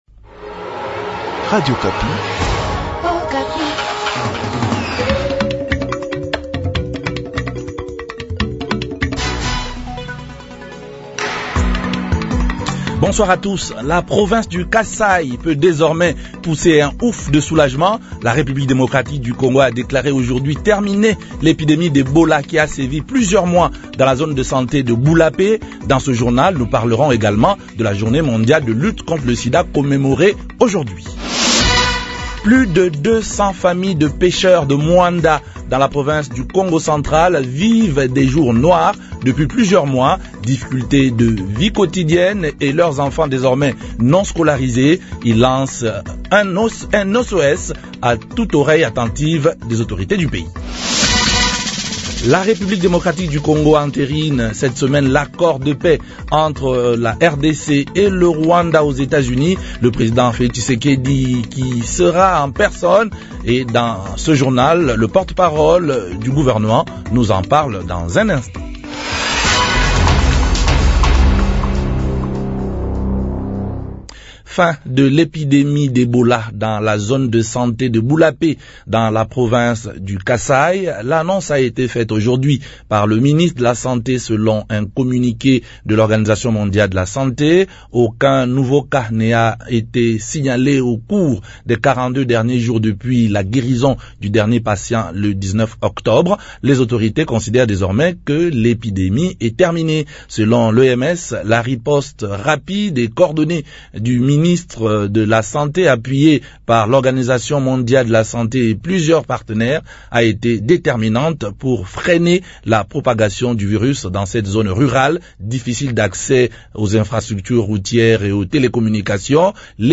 journal francais
Et dans ce journal le porte parole du gouvernement nous en parle dans un instant.